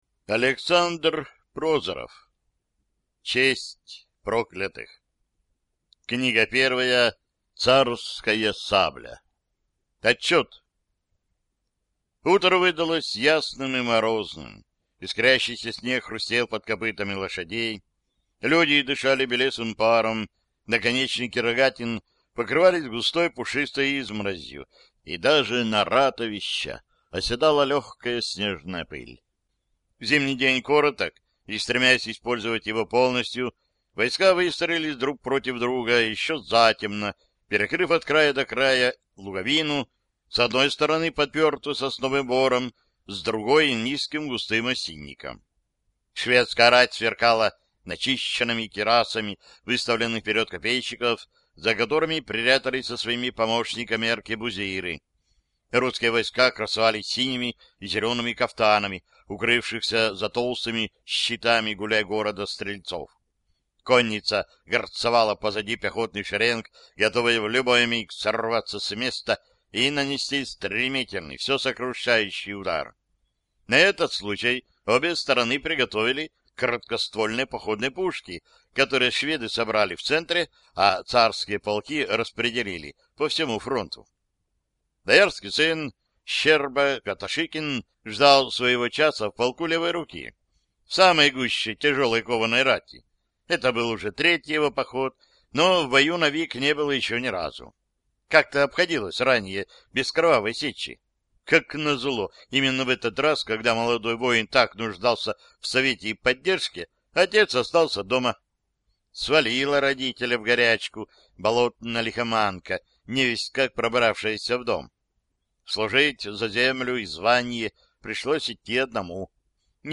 Аудиокнига Царская сабля | Библиотека аудиокниг